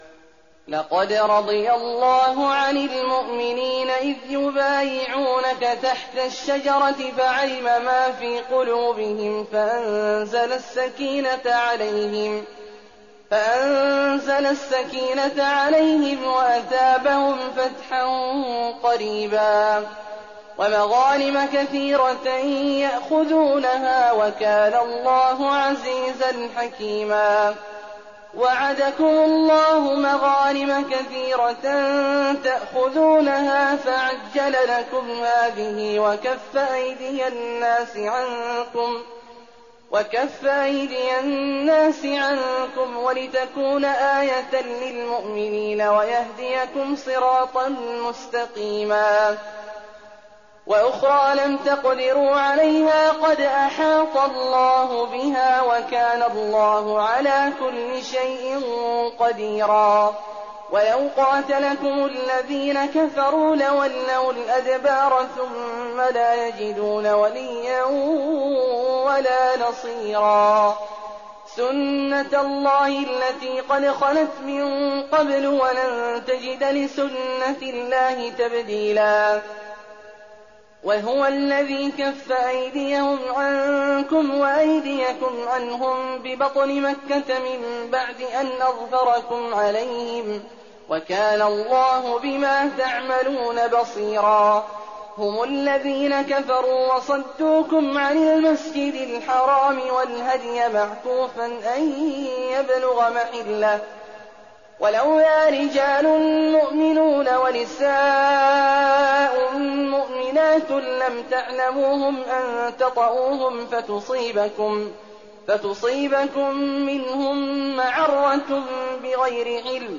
تراويح ليلة 25 رمضان 1419هـ من سور الفتح (18-29) الحجرات و ق و الذاريات (1-37) Taraweeh 25th night Ramadan 1419H from Surah Al-Fath and Al-Hujuraat and Qaaf and Adh-Dhaariyat > تراويح الحرم النبوي عام 1419 🕌 > التراويح - تلاوات الحرمين